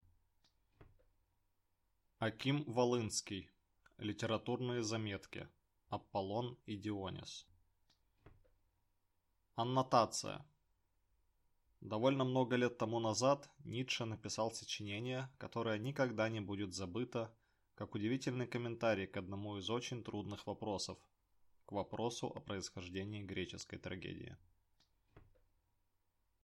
Аудиокнига Литературные заметки: Аполлон и Дионис | Библиотека аудиокниг